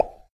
dinDon.a095f3c1.mp3